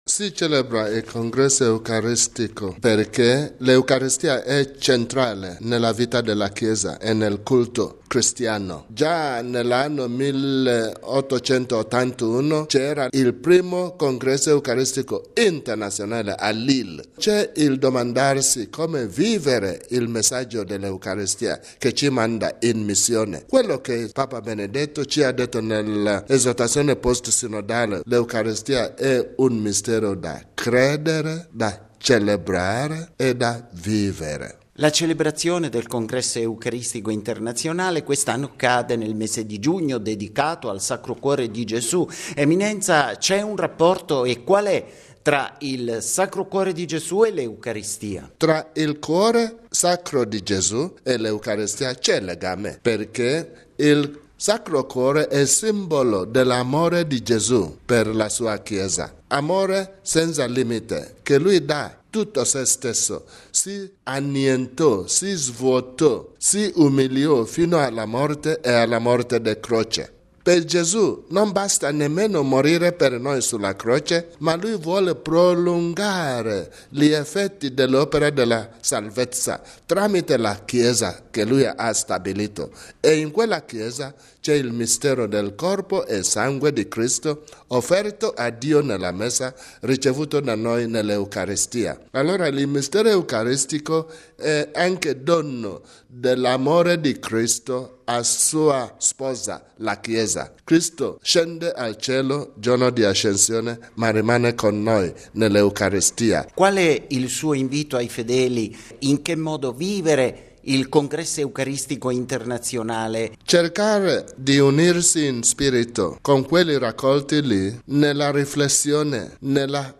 Al via a Québec, in Canada, il 49.mo Congresso Eucaristico Internazionale: intervista col cardinale Arinze